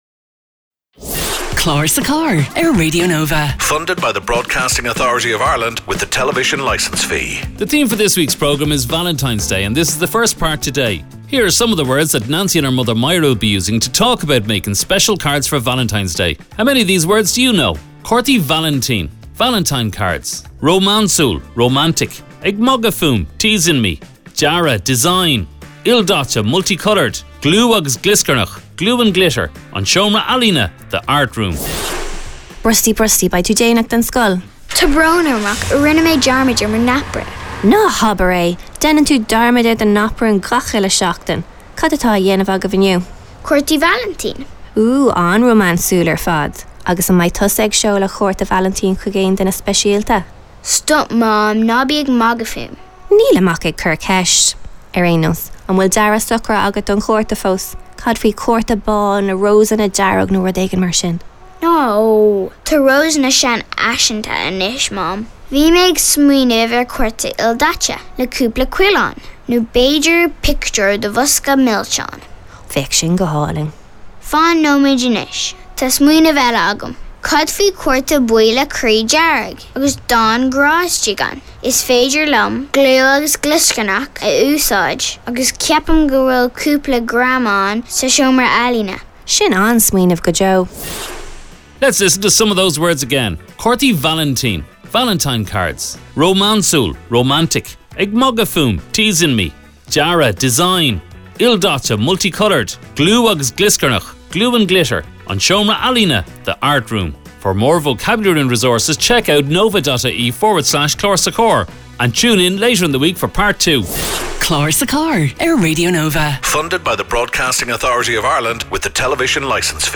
Clár sa Charr is a short conversation between a parent and child that take pace in the car on the way to school.